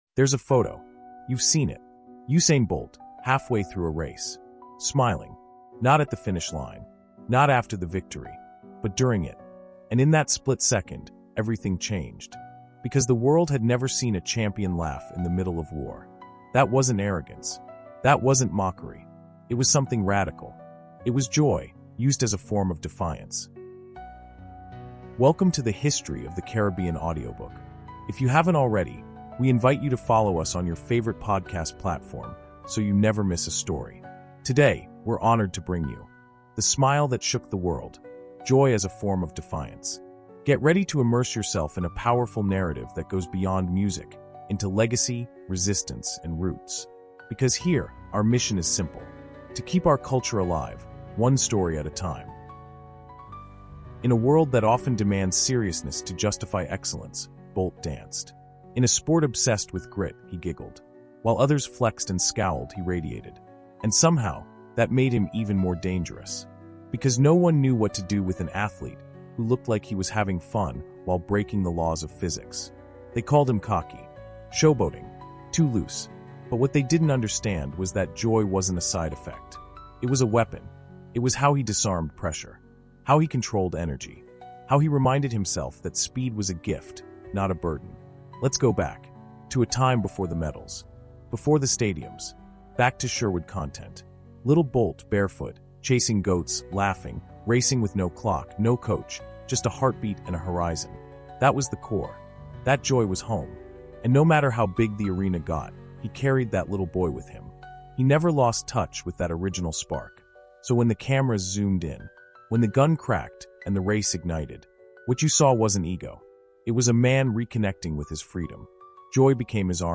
In this radiant 20-minute audiobook insight, we explore how Usain Bolt transformed joy into a tool of domination, using laughter, dance, and ease to disarm opponents, audiences, and expectations alike.